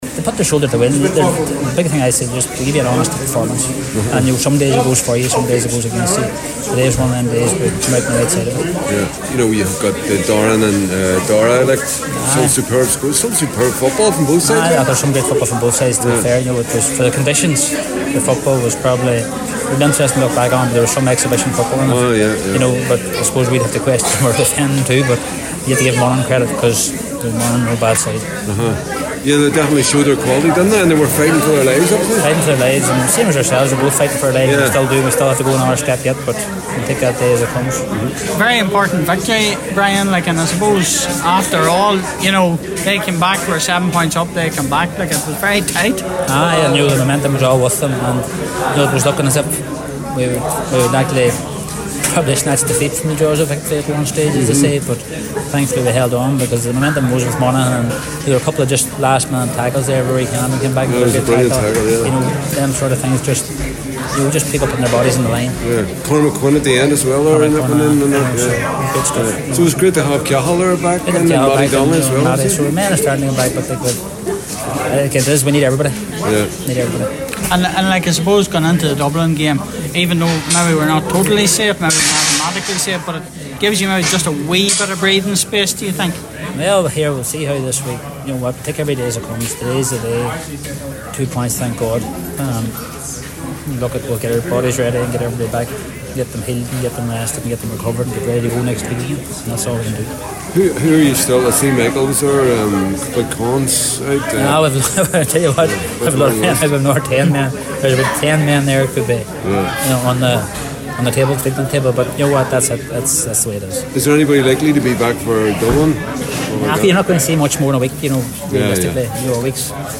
Tyrone joint manager Brian Dooher spoke with the media after the game…